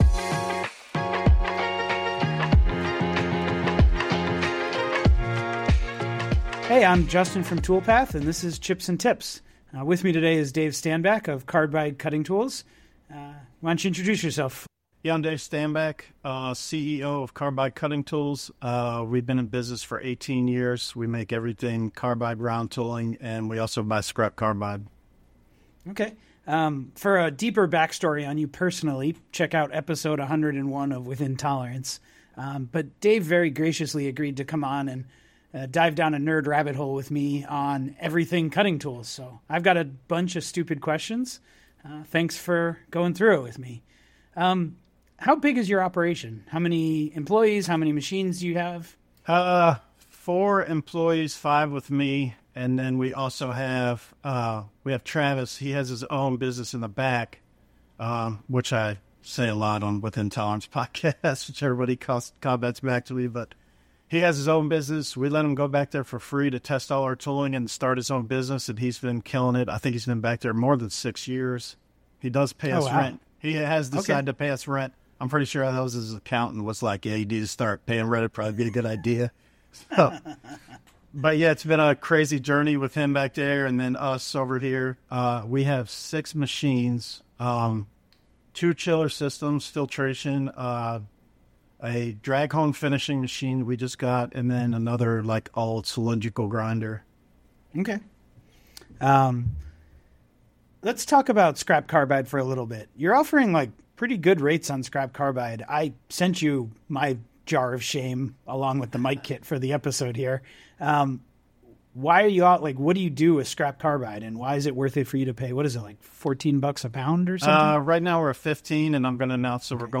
practical conversation about carbide tooling in the real world. They dig into scrap carbide pricing and why it has surged, how custom tools are really quoted and tested, and why a simple sketch can be more effective than a fully detailed CAD model.